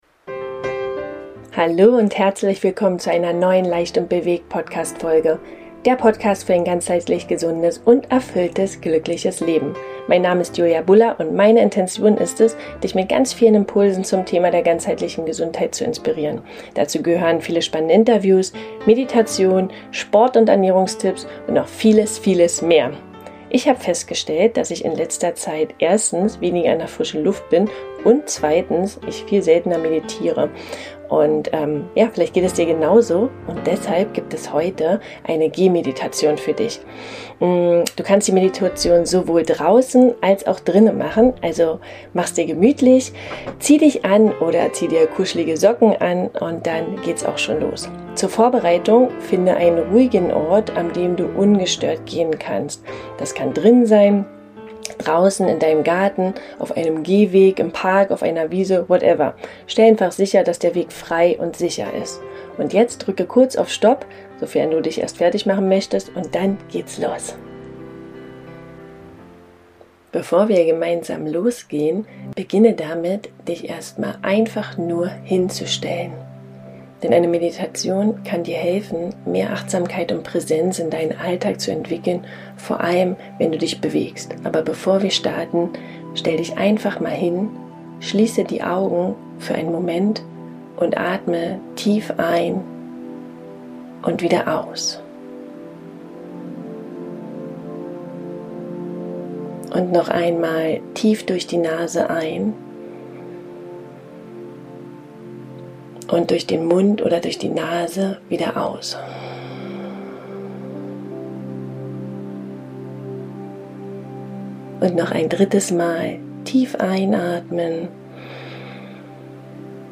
#84 Eine Gehmeditation für drinnen und draußen ~ leicht & bewegt Podcast
Heute wartet eine Gehmeditation auf dich – drinnen oder draußen!